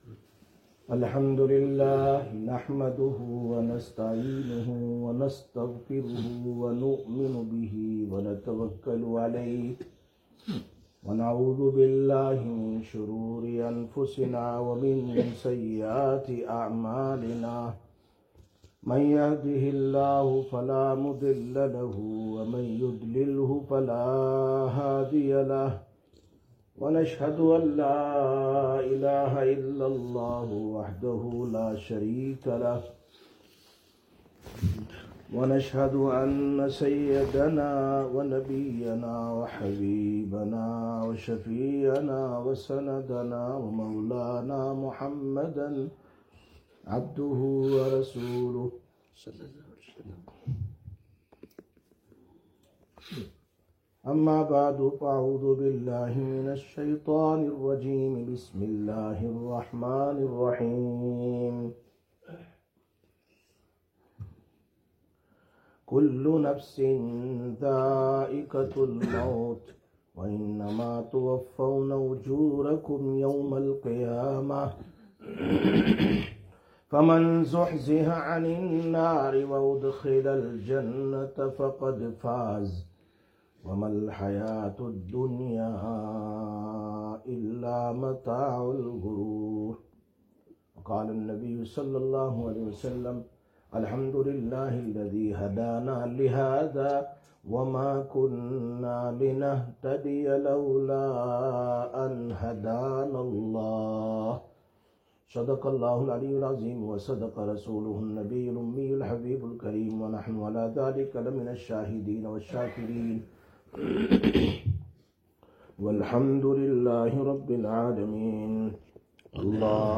19/12/2025 Jumma Bayan, Masjid Quba